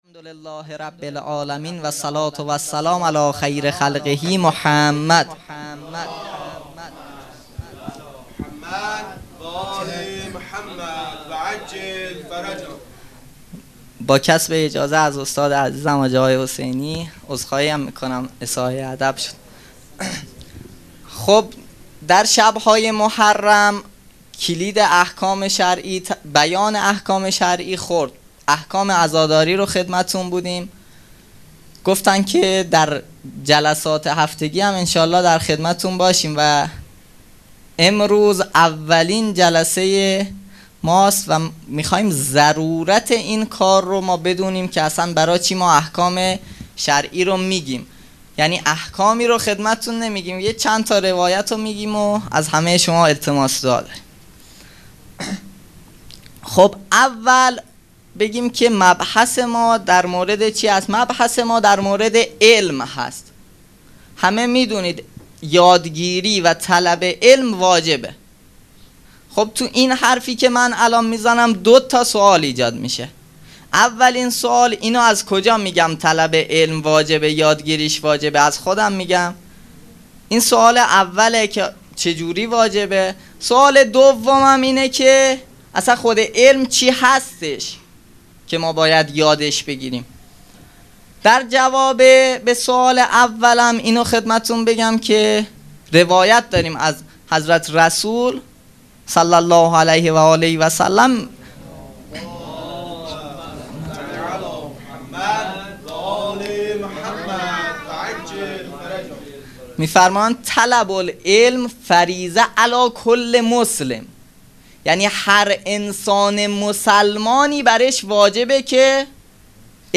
هیئت مکتب الزهرا(س)دارالعباده یزد - منبر احکام توسط